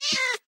mob / cat / hit1.ogg
hit1.ogg